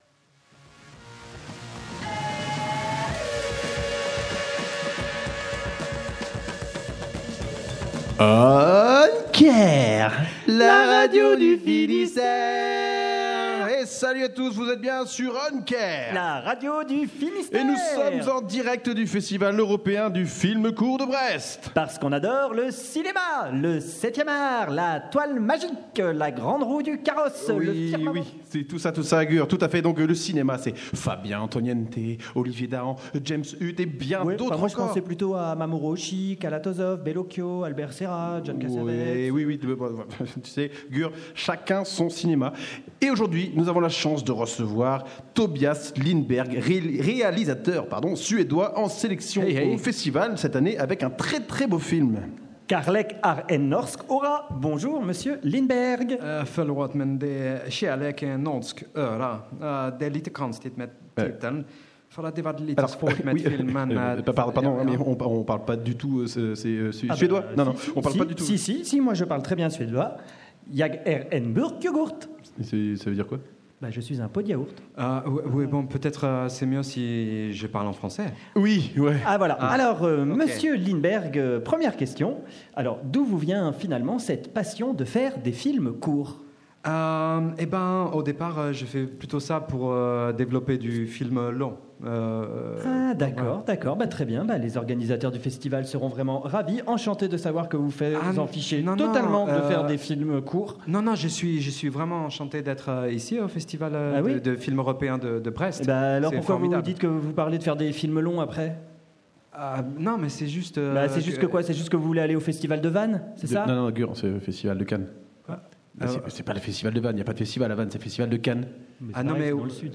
Radio U est en direct de la 34ème édition du Festival Européen du Film Court de Brest, du mercredi 13 au vendredi 15 novembre 2019.